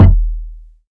kick 29.wav